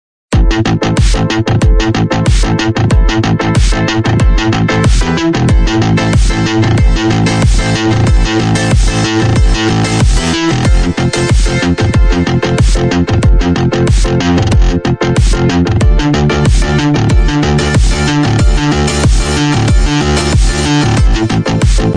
спокойные
без слов
расслабон